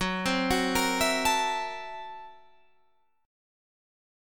Gb7#9b5 Chord